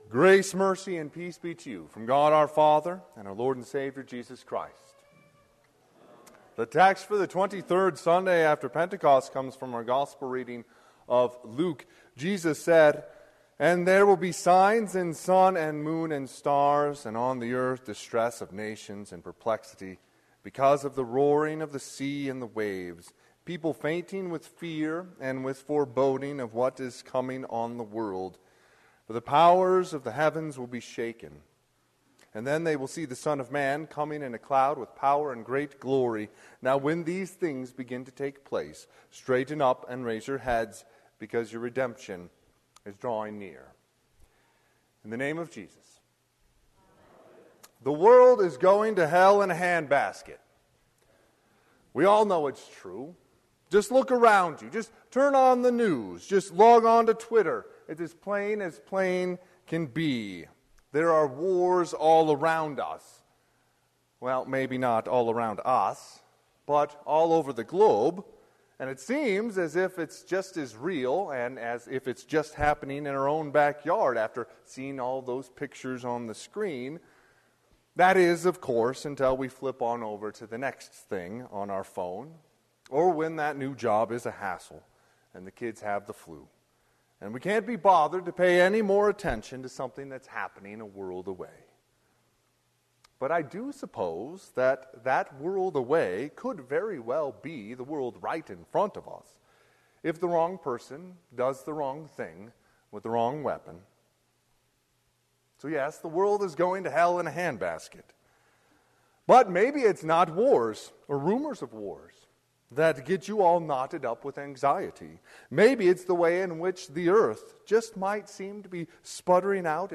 Sermon - 11/13/2022 - Wheat Ridge Lutheran Church, Wheat Ridge, Colorado
Twenty-Third Sunday after Pentecost